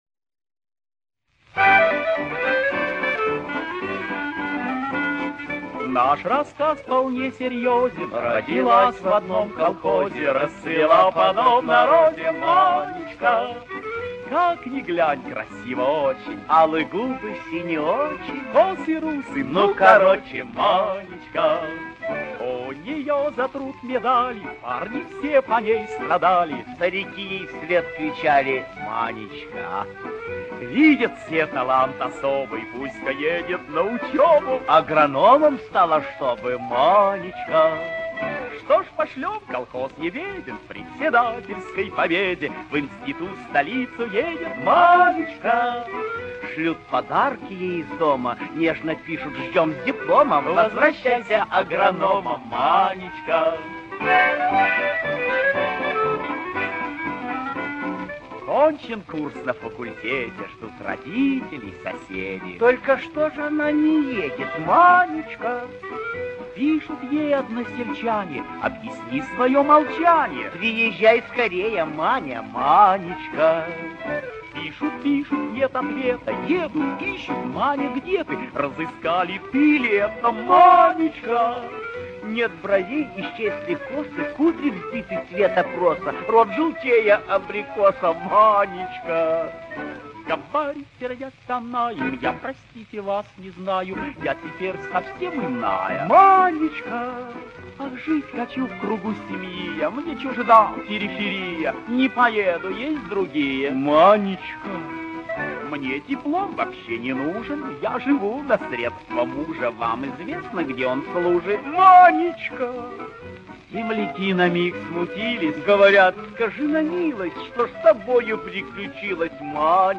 Описание: Песня-фельетон.